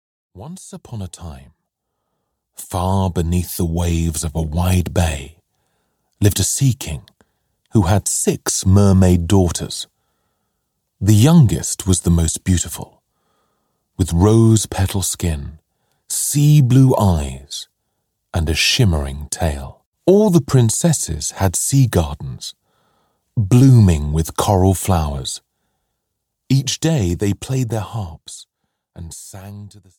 The Little Mermaid (EN) audiokniha
David Walliams reads Hans Christian Andersen most famous fairy tale: "The Little Mermaid". At the bottom of the ocean lives the sea king, his old mother and his six daughters.
• InterpretDavid Walliams